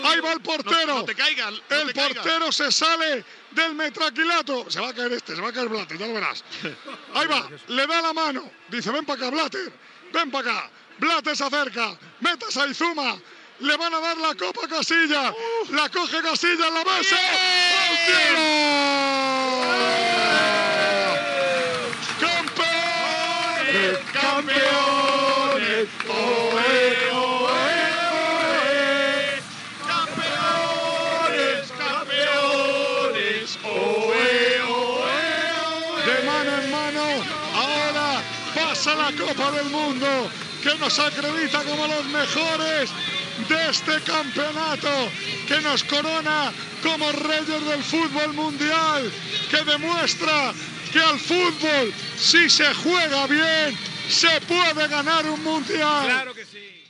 Transmissió del partit de la final de la Copa del Món de Futbol masculí 2010, des de Sud-àfrica.
Narració del moment que el porter Casillas rep la copa.
Esportiu